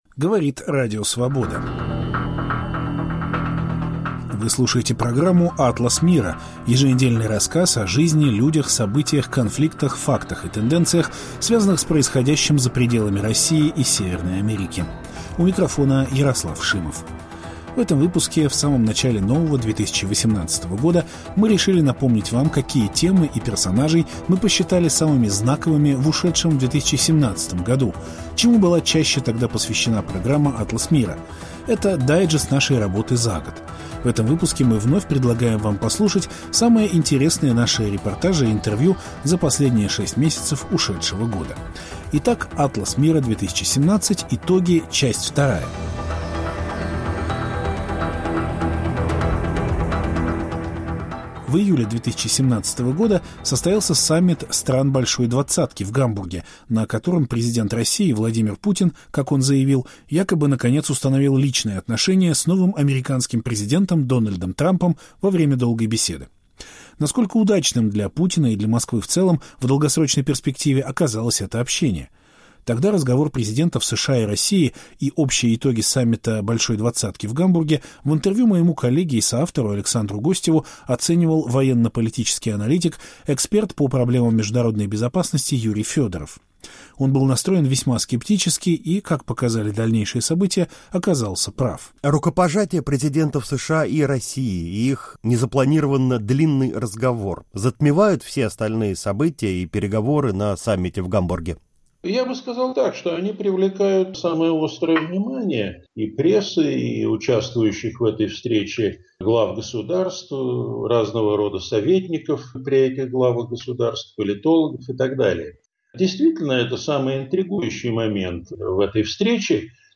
Самые интересные интервью программы "Атлас Мира" второй половины минувшего года